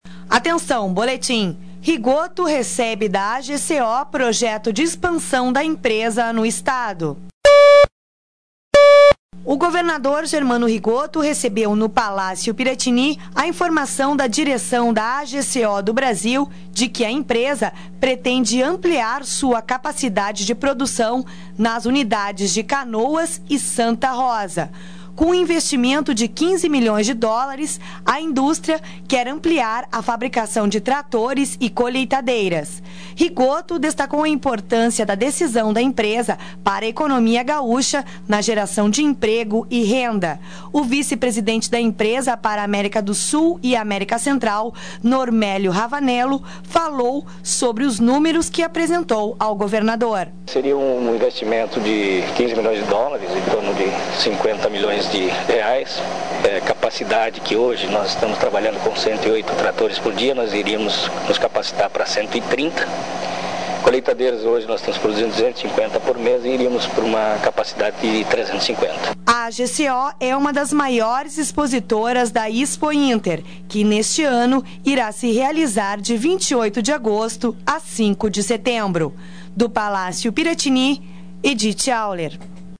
O governador Germano Rigotto recebeu no Palácio Piratini, a informação de direção da AGCO do Brasil de que a empresa pretende ampliar sua capacidade de produção nas unidades de Canoas e Santa Rosa. (sonora